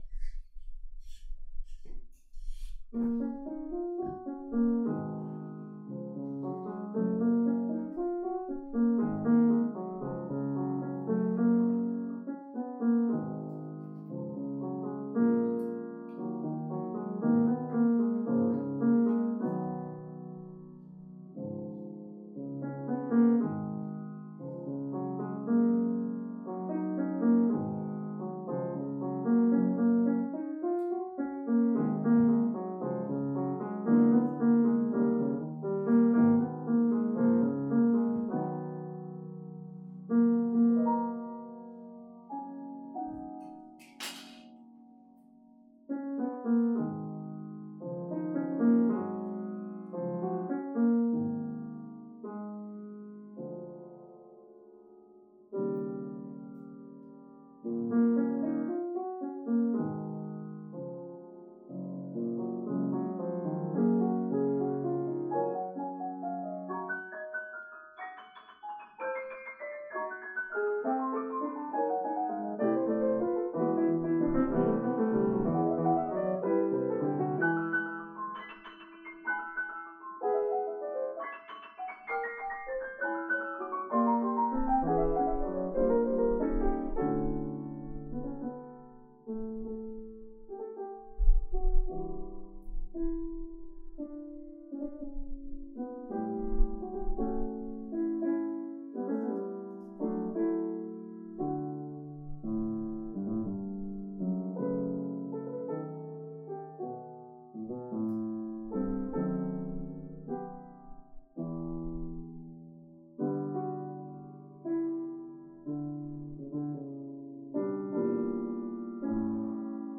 An adequate MIDI recording made morning of 2025 Mar 13
Op. 11 Ballade Composed 2024 (closing theme, being the second theme presented in D major, was composed c. 2014 or 2015).